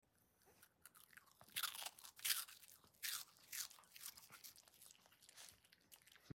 Sound on for extreme cronching!